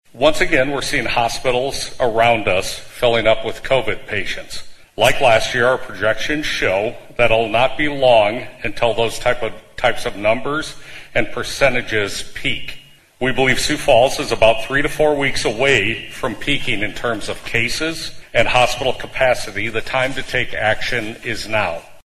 Representatives from Sanford and Avera Health and the Sioux Falls Public Health Department held a joint news conference to outline their concerns about the surge in COVID infections.